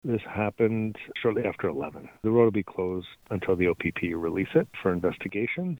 The EMS Chief says this morning’s accident sees River Road remain closed.